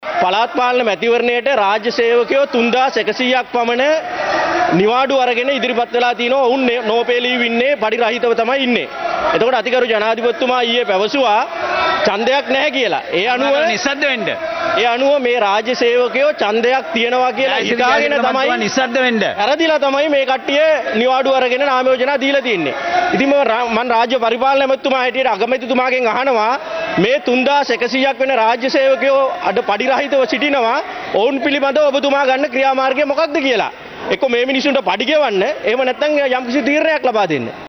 මැතිවරණය පිළිබඳ පාර්ලිමේන්තුවේදී ඉදිරිපත් වූ අදහස්
මැතිවරණ රාජකාරි කටයුතු සදහා මේ වන විට නාමයෝජනා ලබා දුන් රාජ්‍ය සහ අර්ධ රාජ්‍ය සේවකයින් පිළිබඳව පාර්ලිමේන්තු මන්ත්‍රී නාලක ගොඩහේවා මහතා ද මෙලෙස අදහස් දක්වා සිටියා.